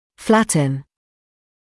[‘flætn][‘флэтн]делать плоским, уплощать; выравнивать